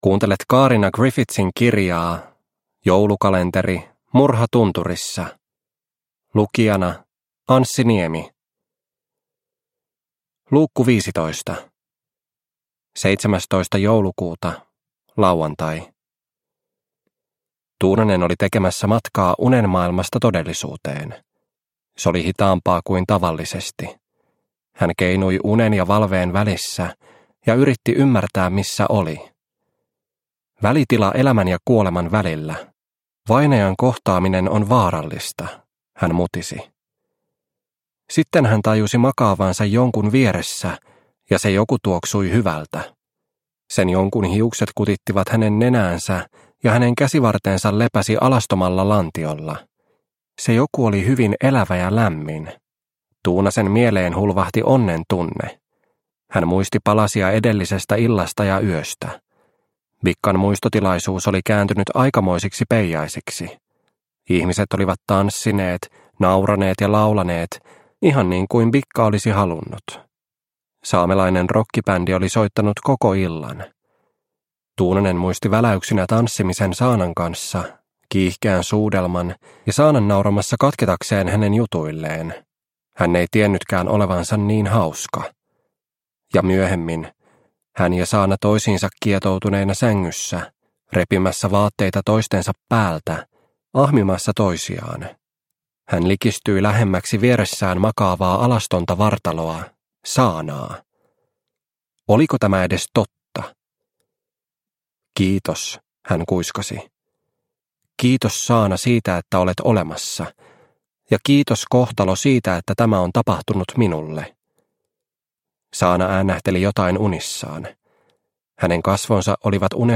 Murha tunturissa - Osa 15 – Ljudbok – Laddas ner